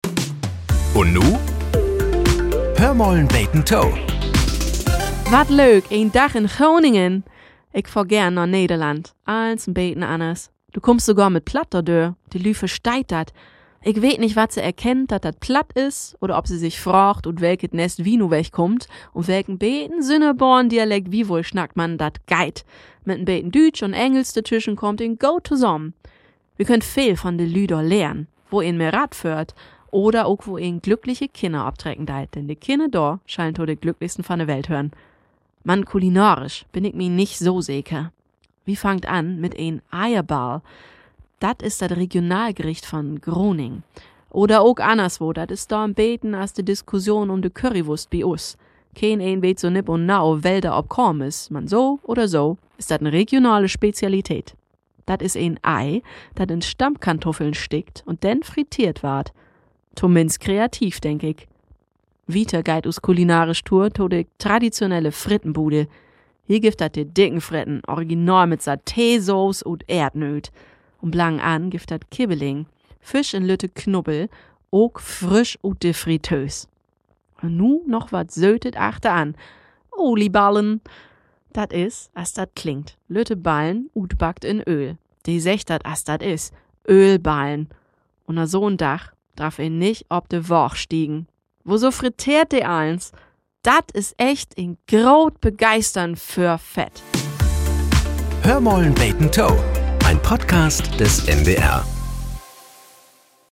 Nachrichten - 29.05.2025